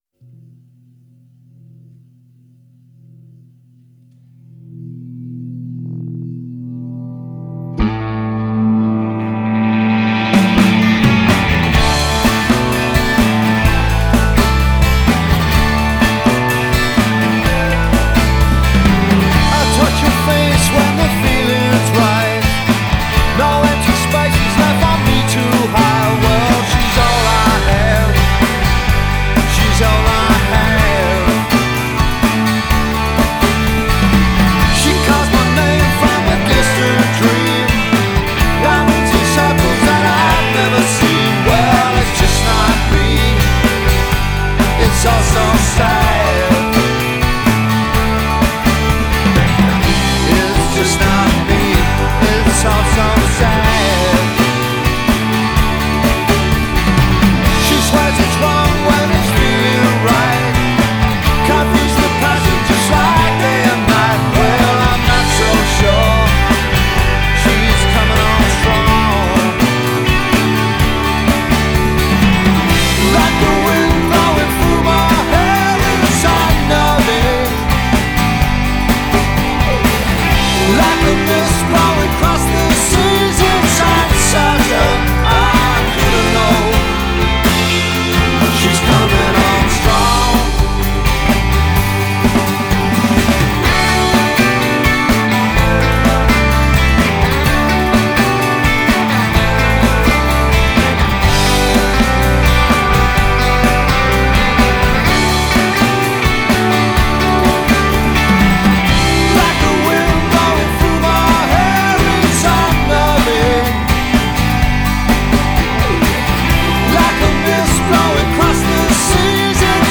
hooky